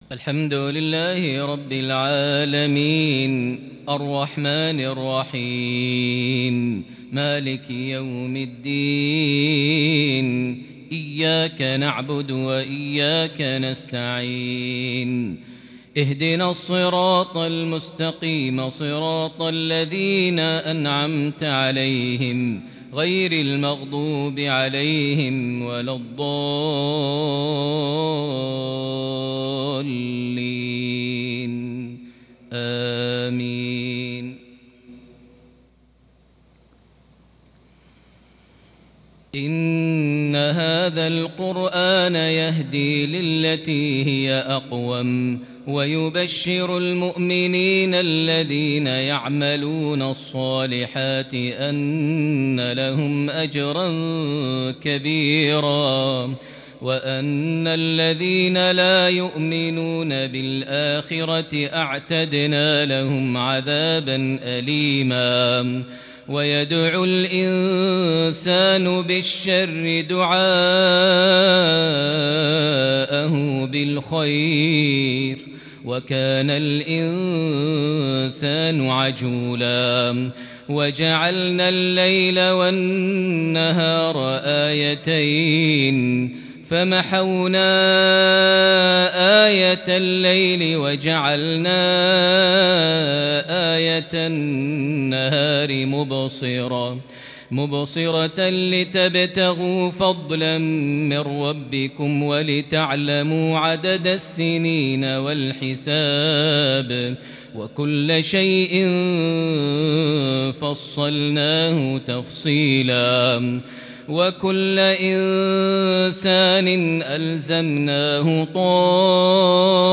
تلاوة بديعة بلمحات كردية من سورة الإسراء (9-17) مغرب 19 ذو القعدة 1441هـ > 1441 هـ > الفروض - تلاوات ماهر المعيقلي